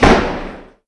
Cerrar una puerta con un portazo fuerte
Sonidos: Hogar